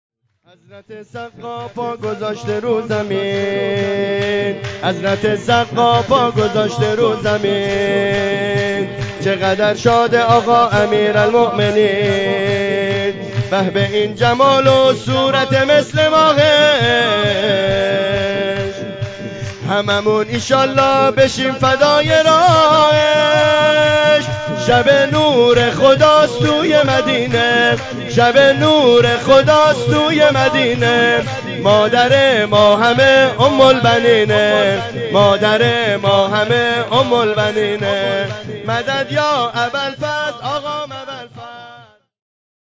جشن اعیاد شعبانیه 1403